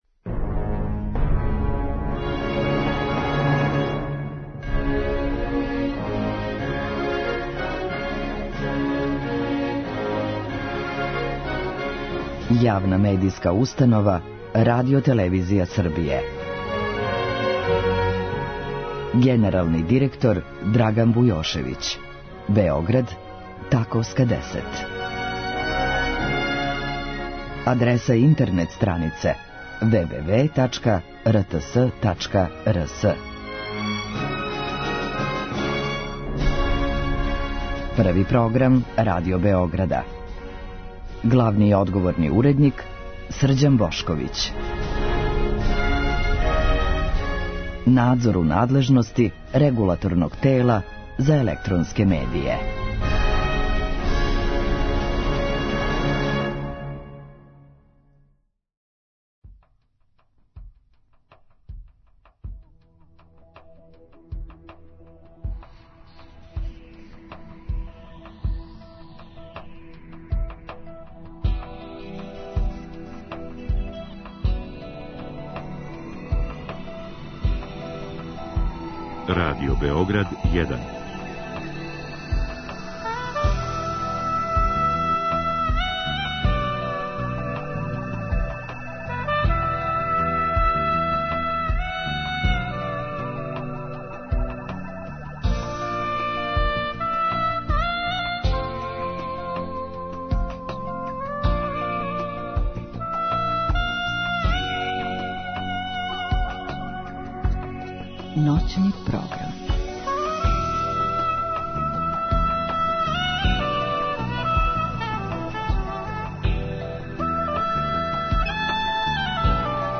Други сат предвиђен је за укључење слушалаца, који у програму могу поставити питање гошћи.